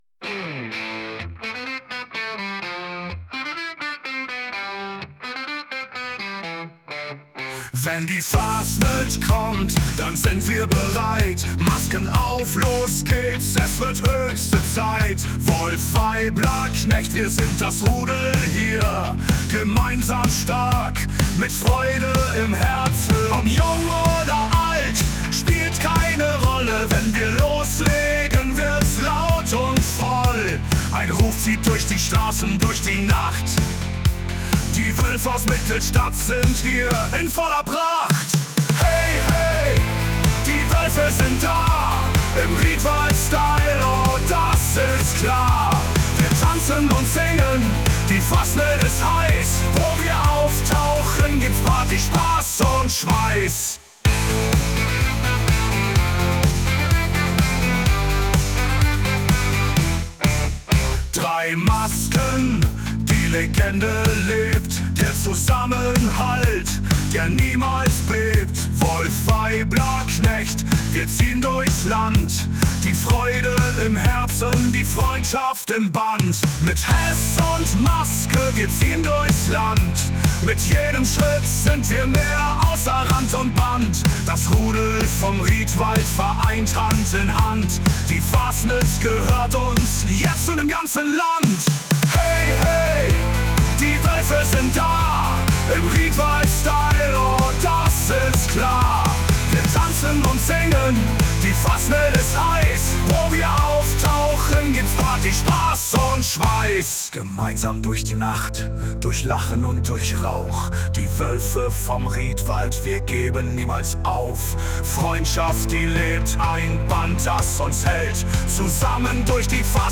Unsere selbstgezimmerten Songs bringen dich zum Lachen, Tanzen und Mitwippen.